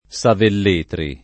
[ S avell % tri ]